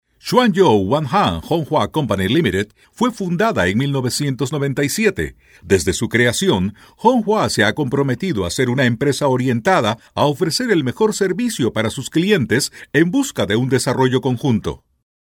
西班牙语样音试听下载
西班牙语配音员（男1）